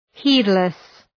Προφορά
{‘hi:dlıs}